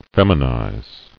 [fem·i·nize]